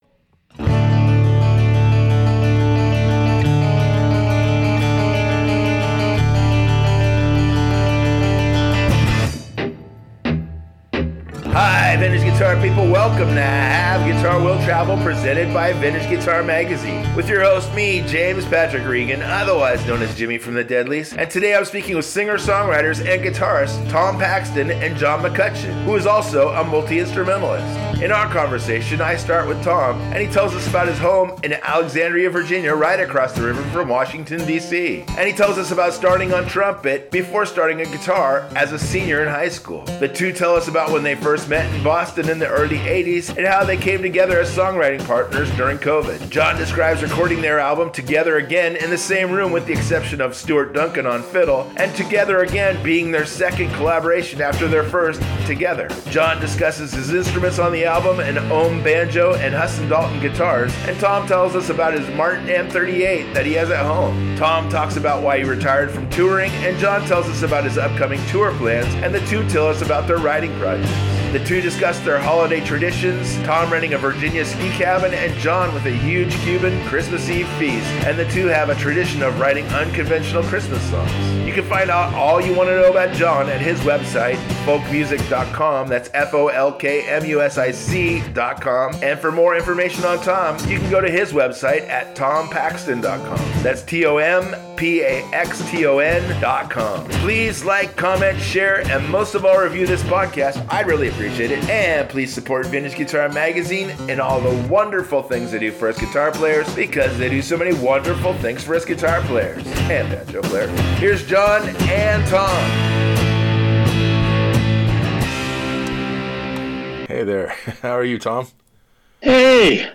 In their conversation he starts with Tom and he tells us about his home in Alexandria, VA, right across the river from Washington, DC and he tells us about starting on trumpet before starting on guitar as a senior in high school. The two tells us about when they first met in Boston in the early ’80’s and how they came together as songwriting partners during Covid.